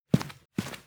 player_walk.wav